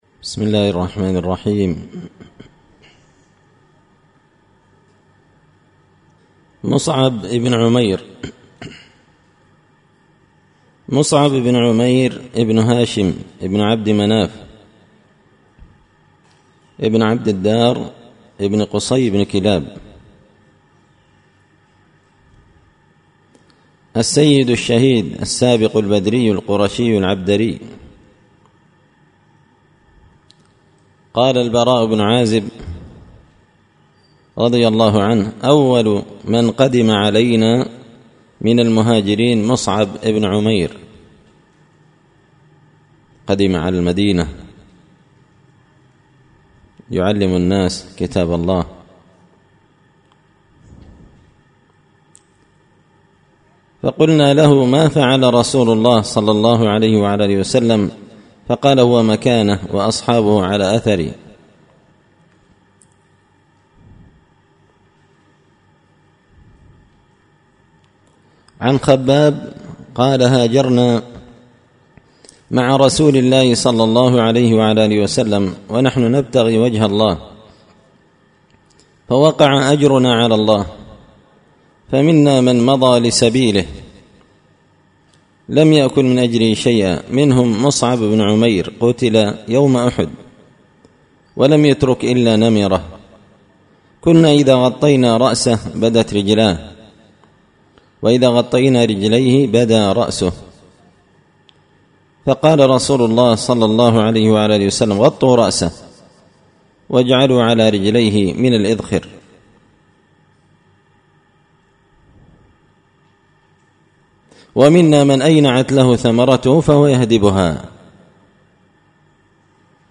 قراءة تراجم من تهذيب سير أعلام النبلاء